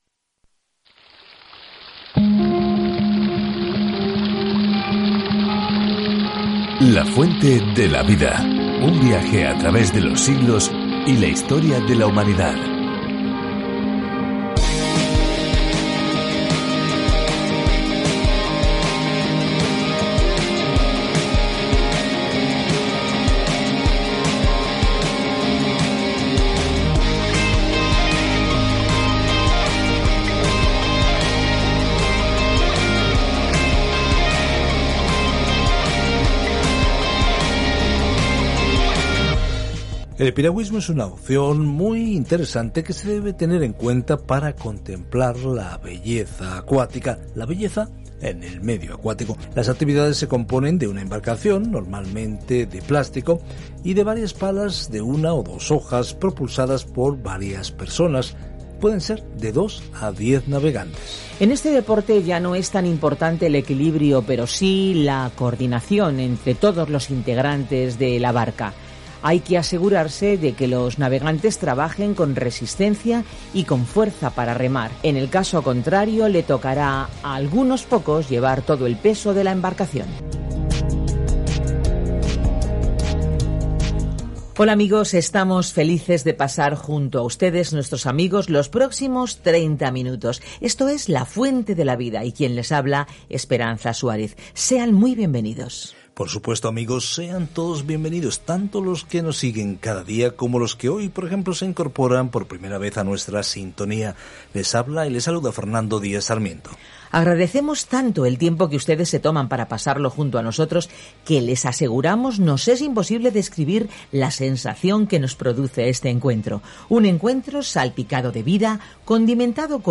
Escritura JUDAS 1:13-16 Día 8 Iniciar plan Día 10 Acerca de este Plan “Luchen por la fe”, dice esta breve pero directa carta de Judas a los cristianos que luchan contra los falsos maestros que se han infiltrado en la iglesia sin ser advertidos. Viaja diariamente a través de Judas mientras escuchas el estudio en audio y lees versículos seleccionados de la palabra de Dios.